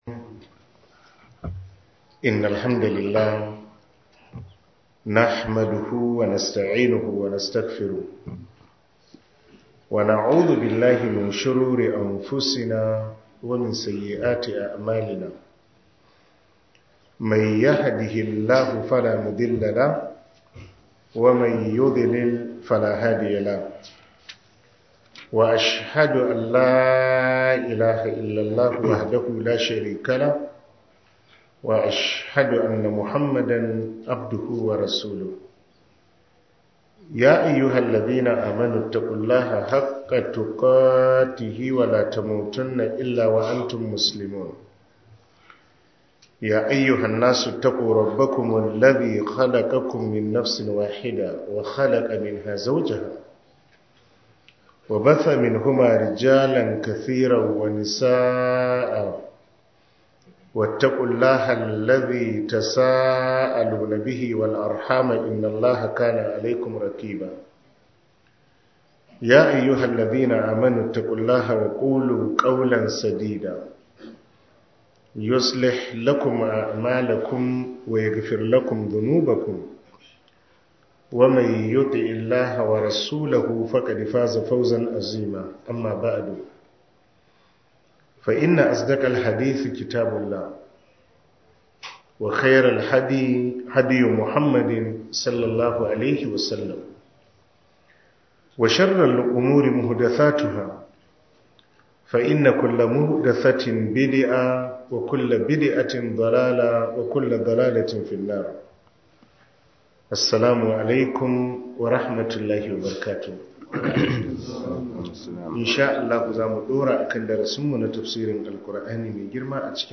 ← Back to Audio Lectures 14 Ramadan Tafsir Copied!